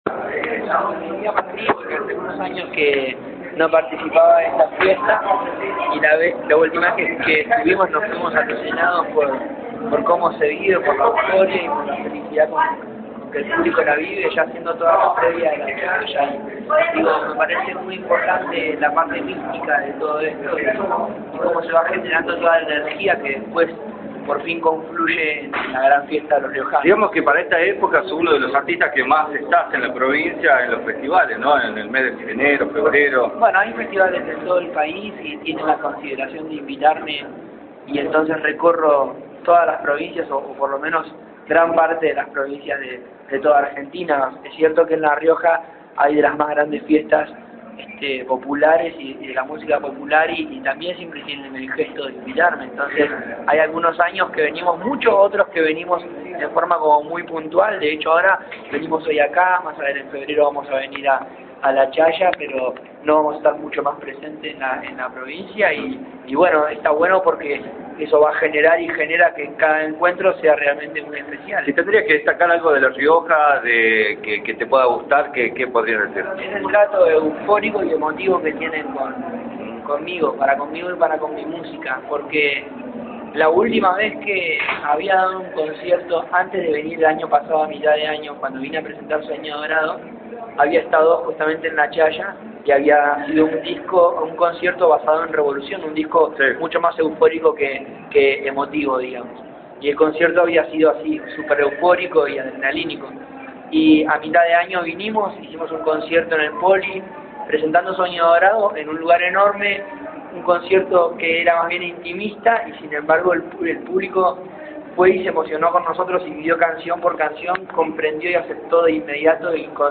Conferencia de prensa de Abel Pintos